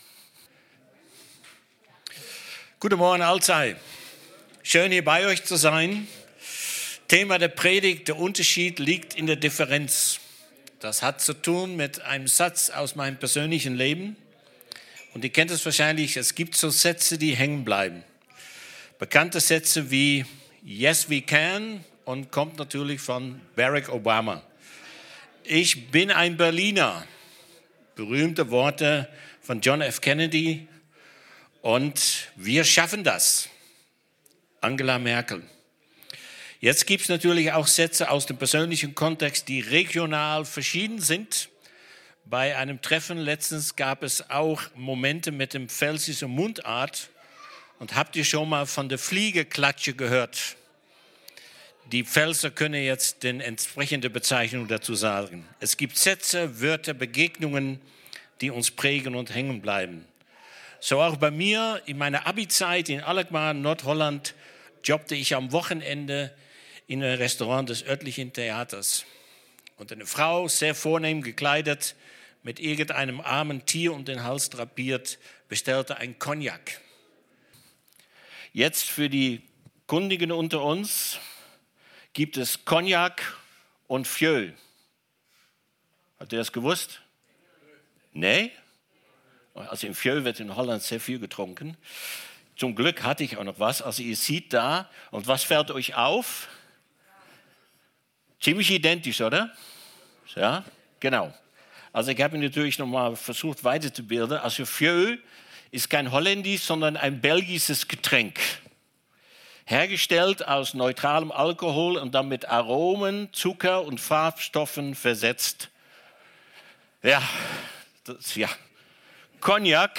Predigt vom 07.12.2025 | Podcast der Stadtmission Alzey